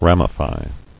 [ram·i·fy]